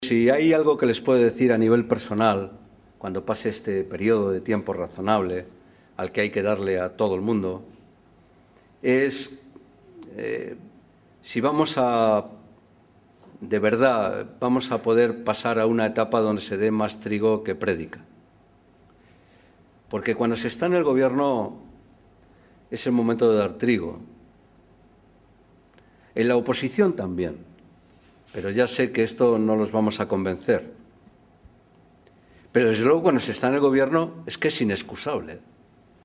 Fernando Moraleda, diputado nacional del PSOE
Cortes de audio de la rueda de prensa